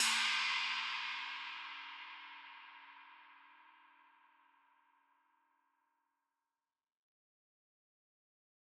Crashes & Cymbals
China Zion.wav